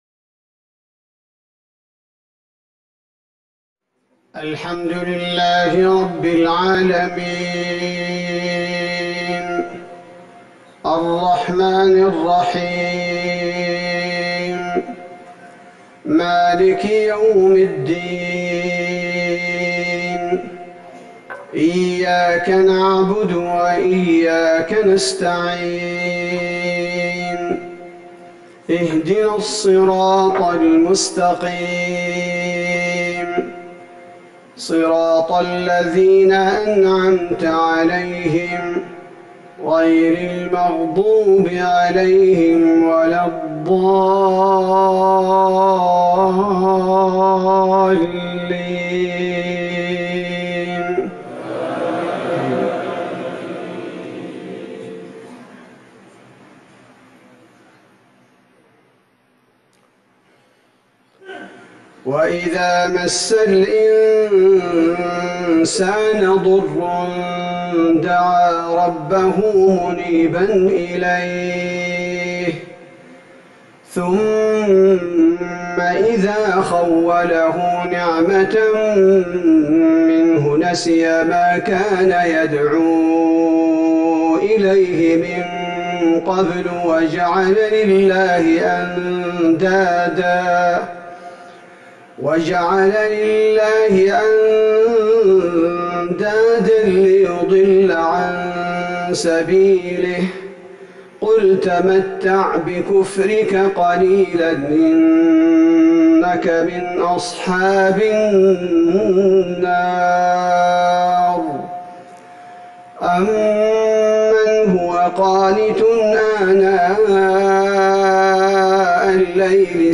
صلاة العشاء ١٤ جمادى الآخرة ١٤٤١هـ سورة الزمر | Isha prayer 8-2-2020 Sura al-Zamer > 1441 🕌 > الفروض - تلاوات الحرمين